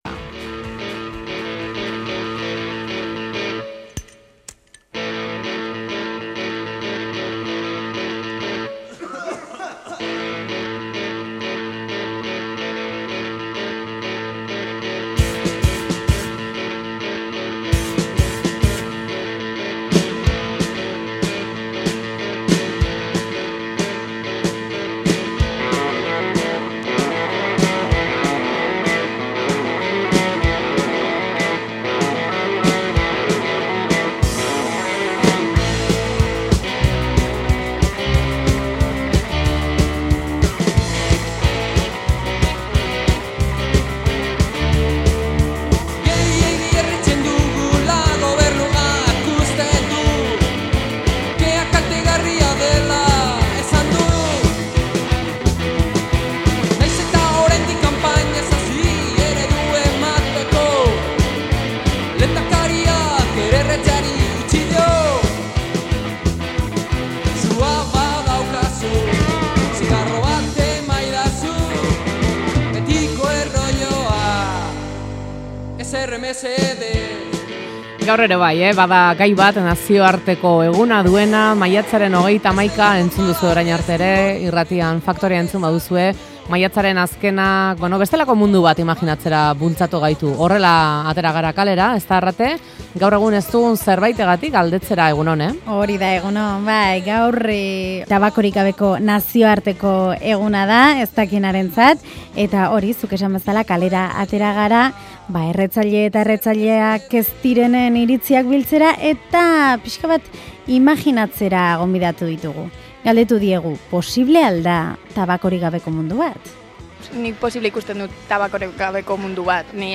Gaur, maiatzak 31, Tabakorik Gabeko Nazioarteko Eguna da. Faktoria kalera atera da erretzaile eta erretzaile ez direnen iritziak biltzera.